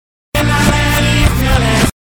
Genere: alternative rap